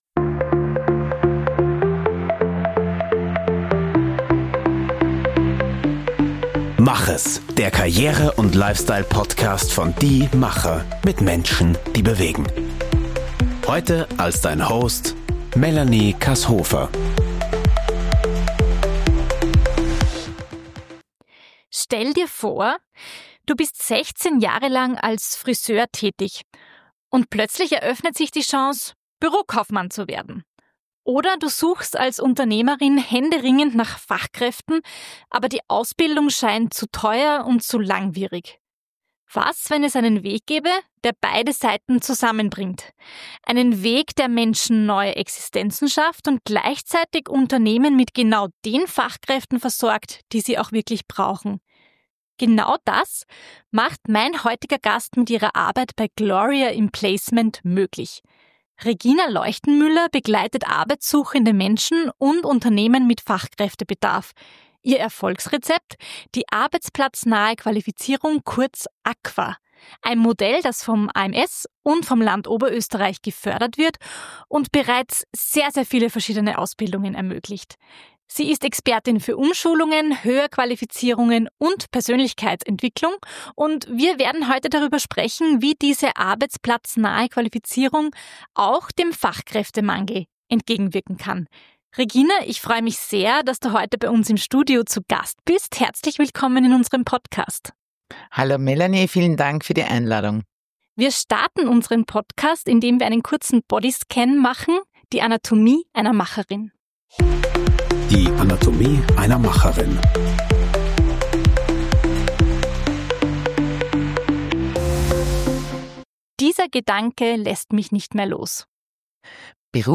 Ein Gespräch über ein zukunftsweisendes Modell, das Menschen Mut machen soll, die gerne in einem neuen Berufsfeld tätig werden möchten, aber bisher nicht wussten, wie sie die Umschulung finanzieren oder organisieren sollen.